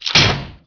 beartrap.wav